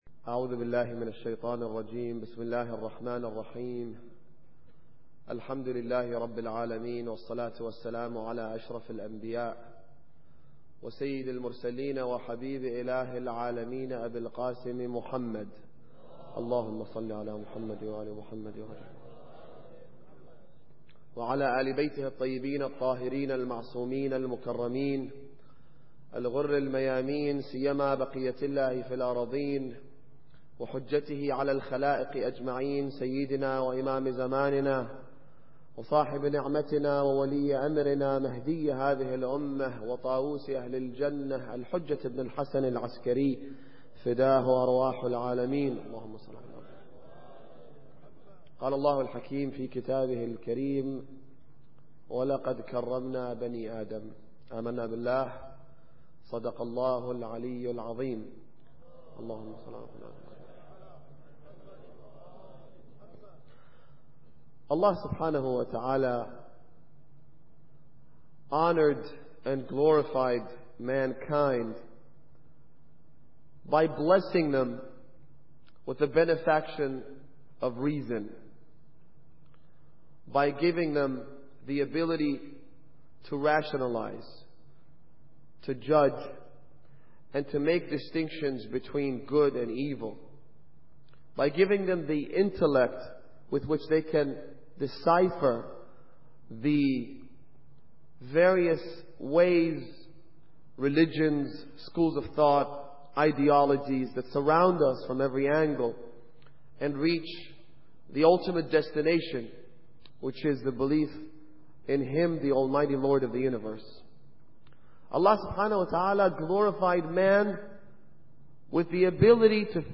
Muharram Lecture 11